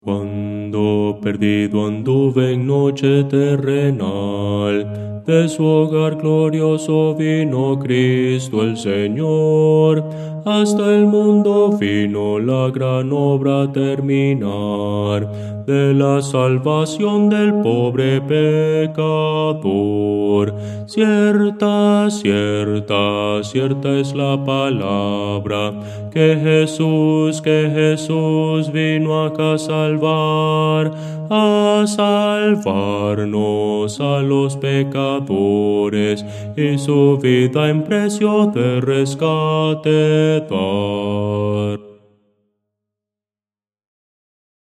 Voces para coro